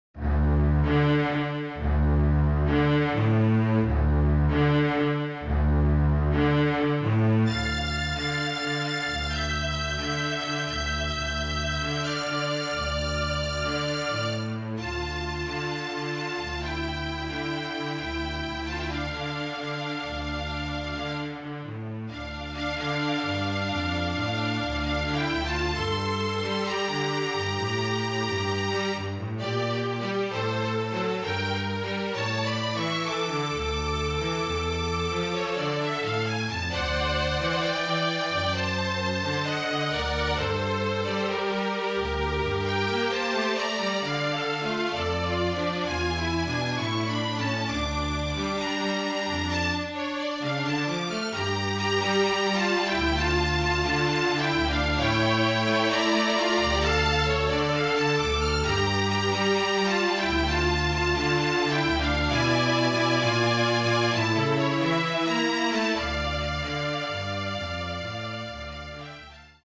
FLUTE TRIO
Flute, Violin and Cello (or Two Violins and Cello)
MIDI